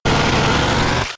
Cri de Dardargnan K.O. dans Pokémon X et Y.